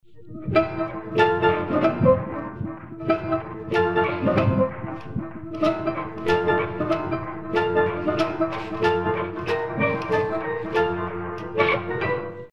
Also das Piano ist nicht mehr rettbar mit Saturation würde ich sagen, da fehlt zu viel.